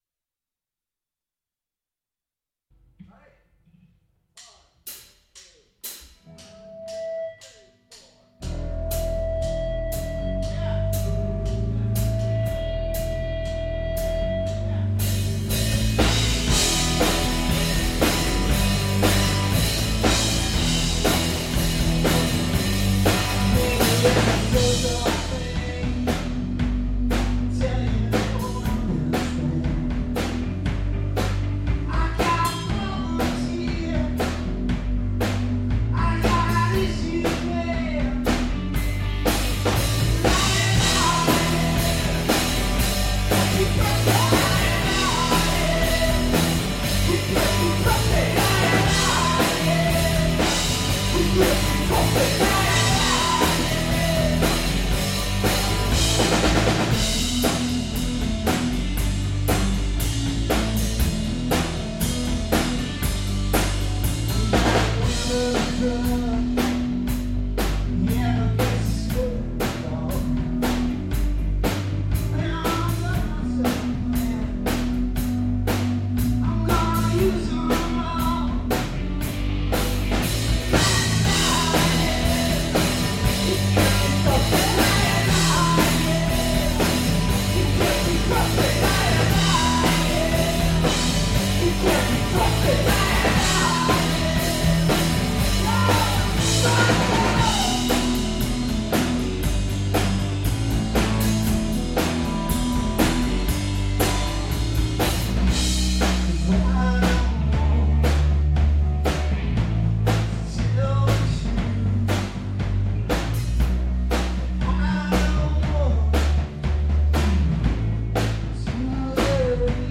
Liar Liar - Faster Tempo 329